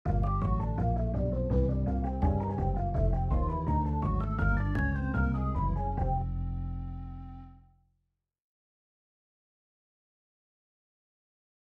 12小節の定番ブルース進行
進行は‖Gm7｜C7｜Ｆ7/D7｜Gm7/C7‖になります。
サンプル音源　普通に、オーソドックスな歌い廻しです。4小節の3・4拍目のC7はC Alterdですね。